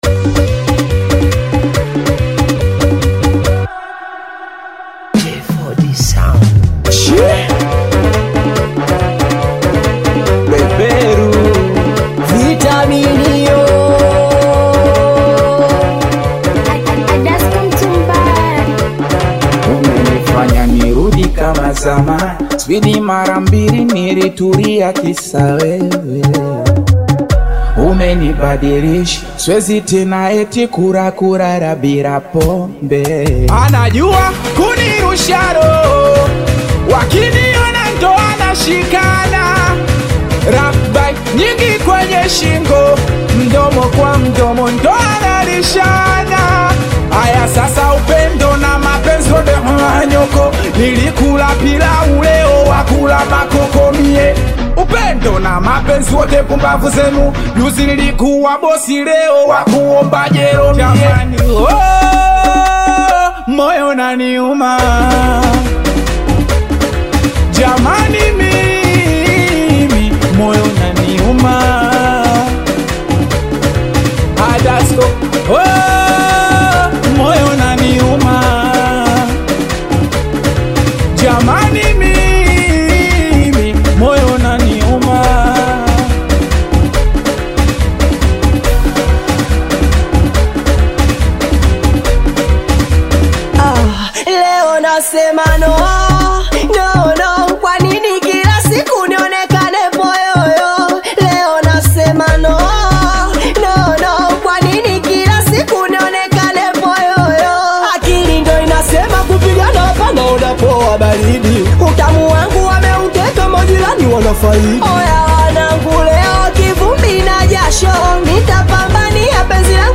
Tanzanian Bongo Flava Singeli
Singeli song